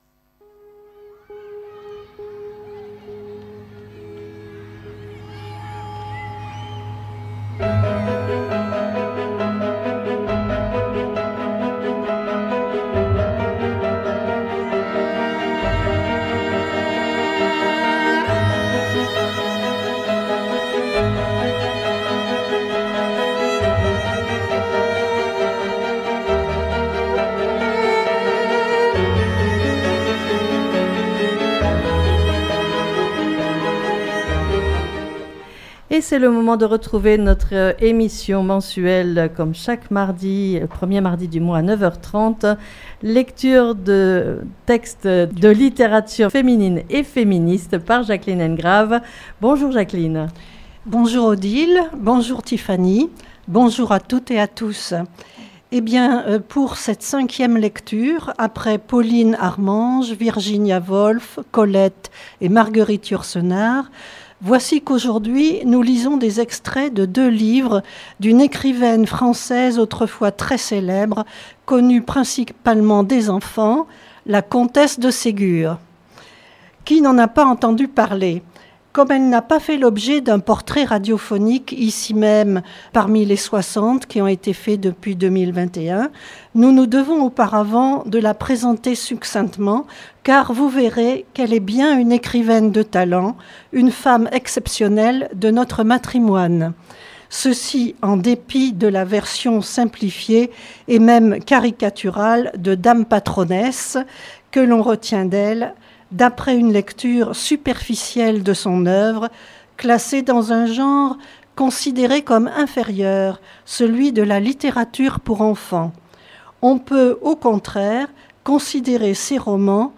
Lecture de textes de littérature féminine et féministe